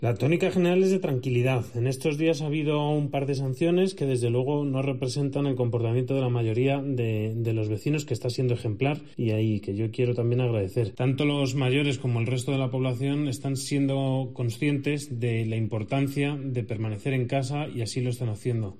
El alcalde de Majadahonda destaca que tan solo se han puesto dos sanciones por incumplir la cuarentena